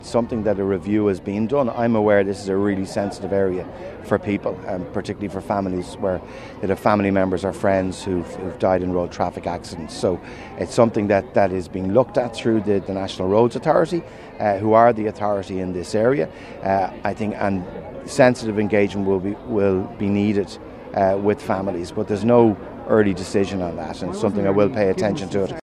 Transport Minister Darragh O’Brien says there’s no definitive decision on the plans……………